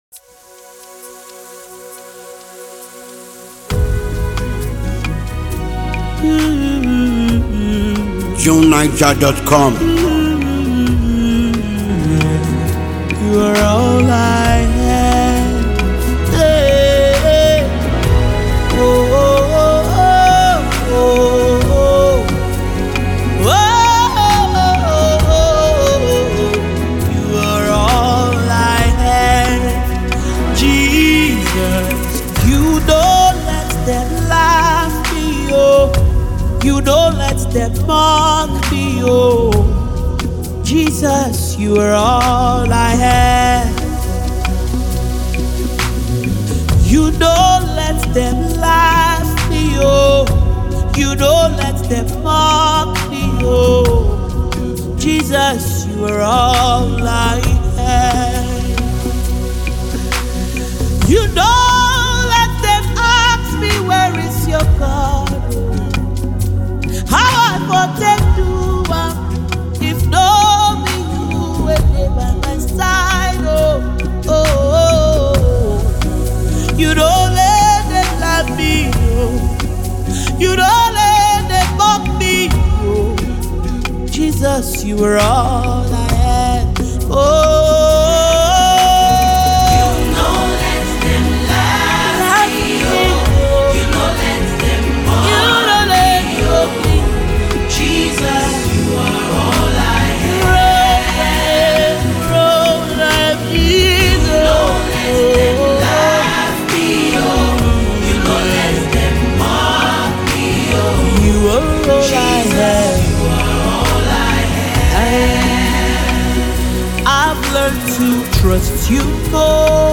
a remarkable Nigerian gospel singer
Anyone looking for soul-stirring music should have it.